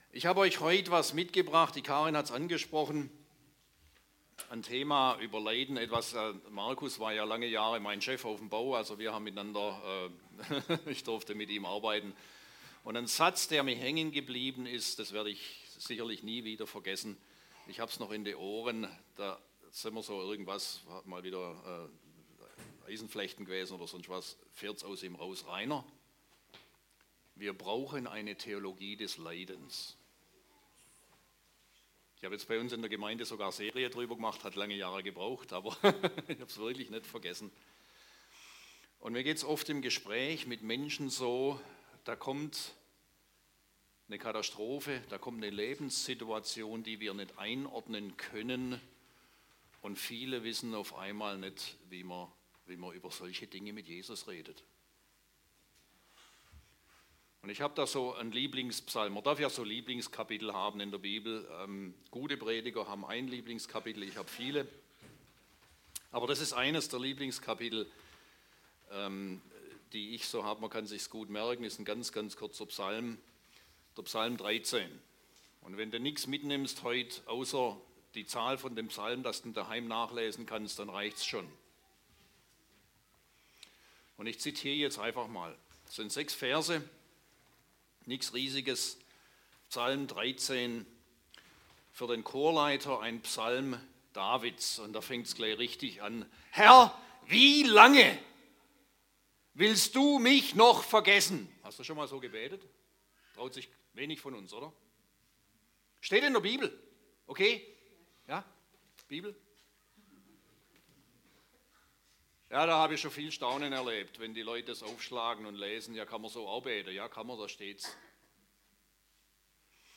Download der Audiodatei  Kategorie Predigten  Kategorie David